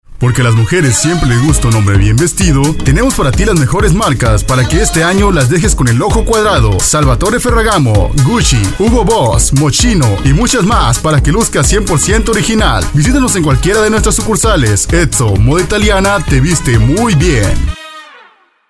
Sprechprobe: Werbung (Muttersprache):
Amateur voice actor/announcer